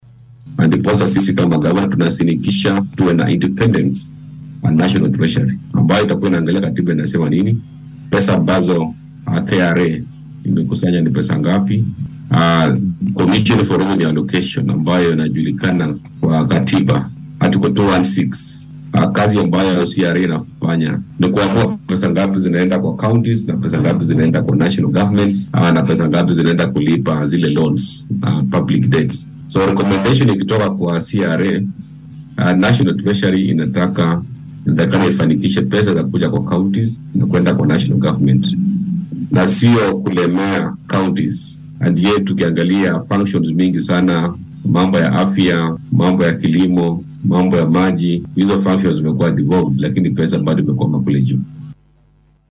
Waxaa uu carrabka ku adkeeyay in tani ay meesha ka saareysa caqabadaha ay ismaamullada ku qabaan lacagaha ay dowladda sare siisa ee ka soo dib dhaca. Arrintan ayuu ka hadlay xilli uu wareysi gaar ah siinayay idaacadda maxalliga ee Radio Citizen.